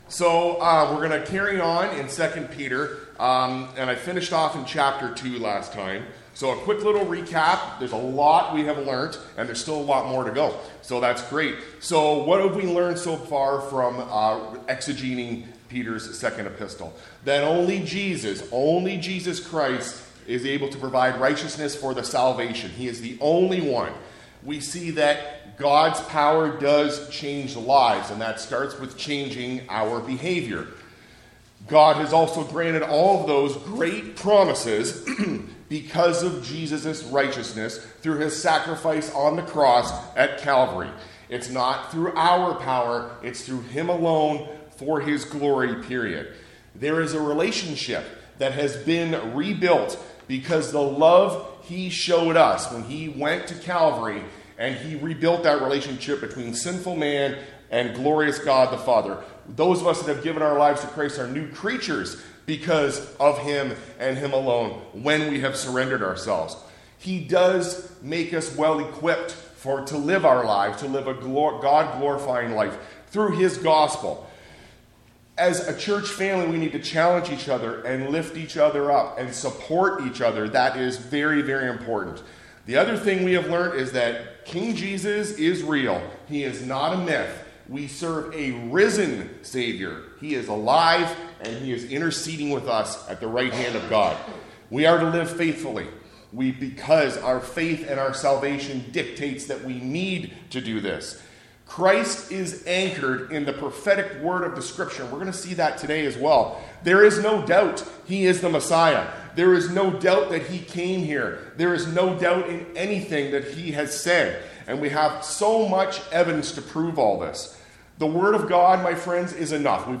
A message from the series "The Word Together."